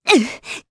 Hilda-Vox_Damage_jp_01.wav